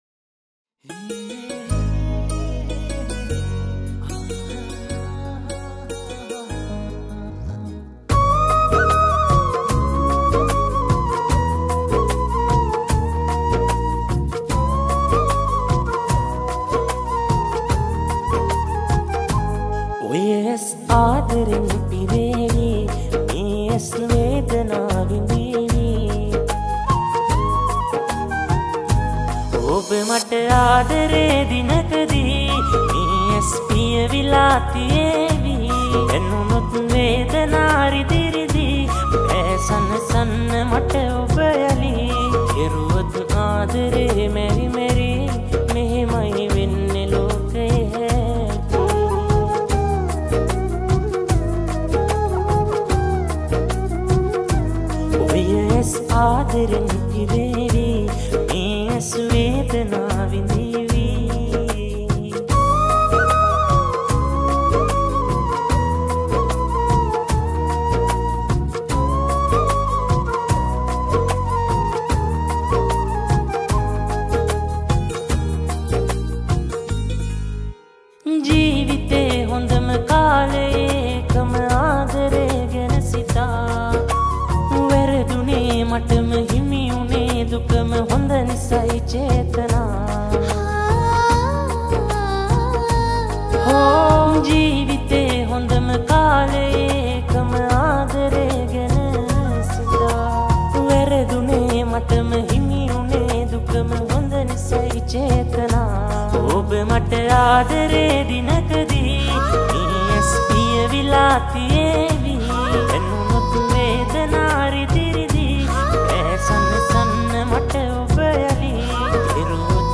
This Is a romantic Song.